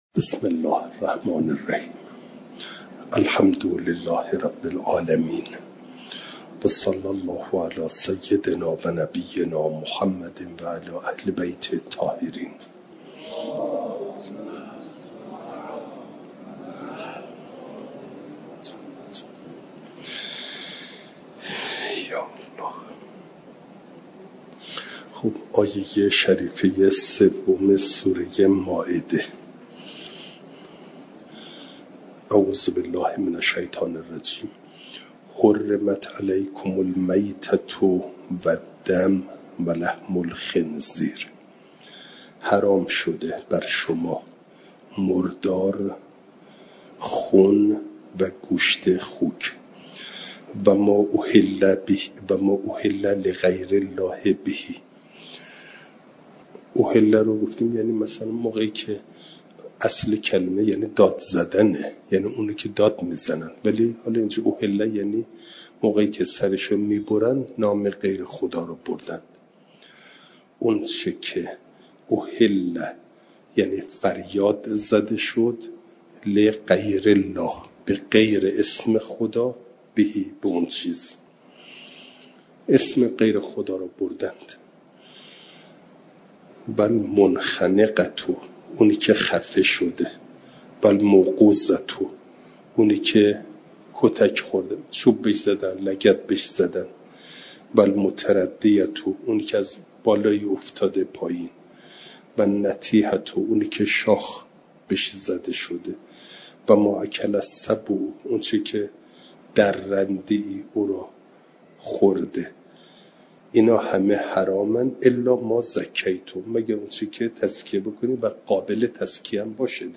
جلسه چهارصد و پانزده درس تفسیر مجمع البیان